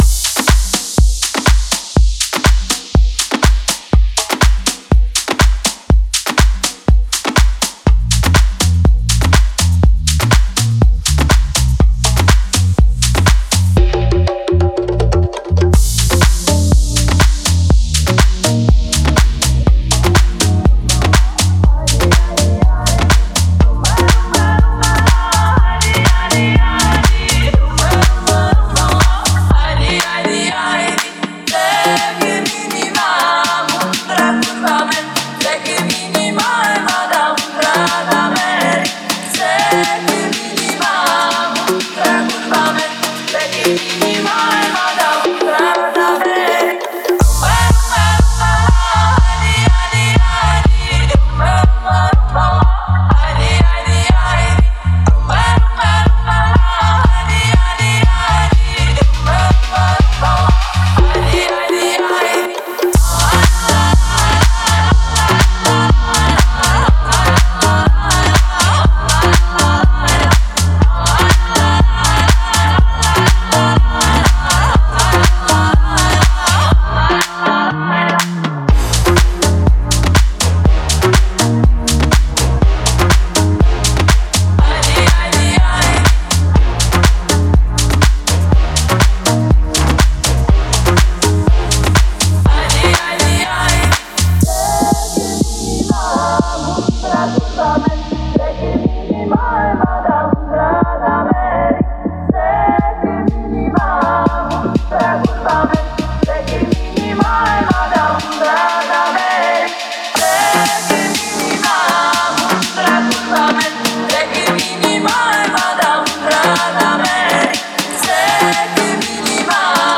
выполненная в жанре электронной танцевальной музыки.